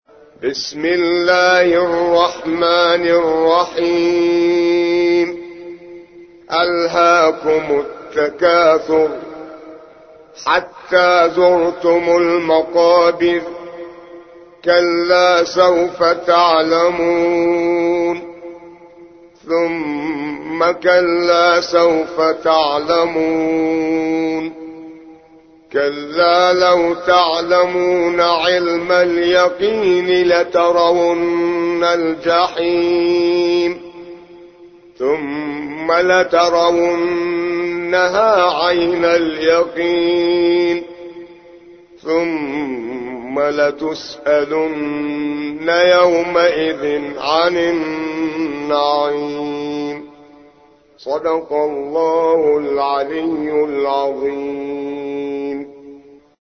102. سورة التكاثر / القارئ